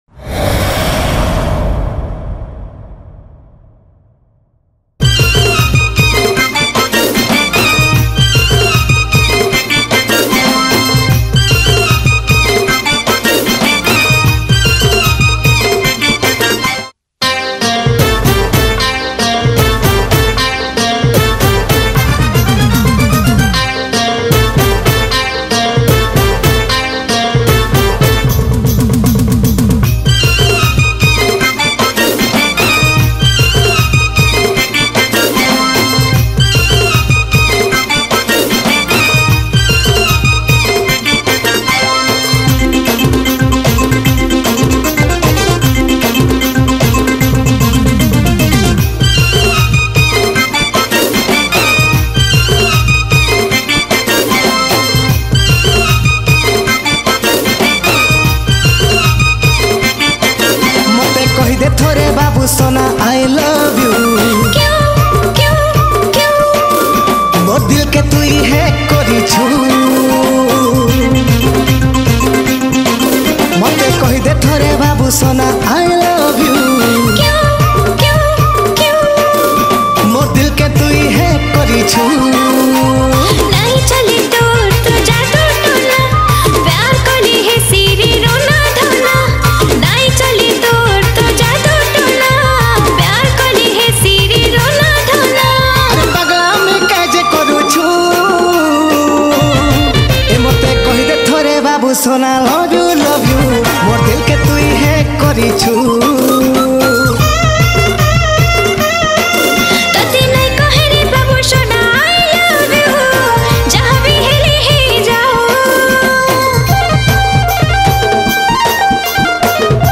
Sambalpuri Songs